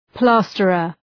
Προφορά
{‘plæstərər} (Ουσιαστικό) ● σοβατζής